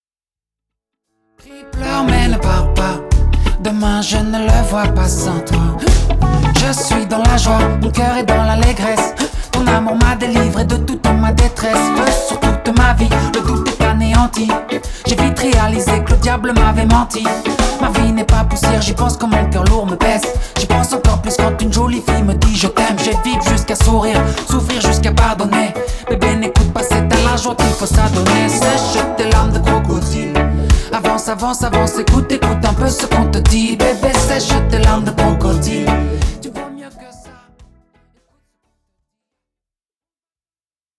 reggae
Enregistré dans un grand studio de Bruxelles
ligne de basse sautillante